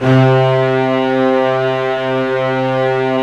Index of /90_sSampleCDs/Roland L-CD702/VOL-1/CMB_Combos 2/CMB_Bryt Strings